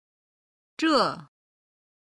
着　(zhe)　・・している
※軽声の「zhe」は四声で表現しています。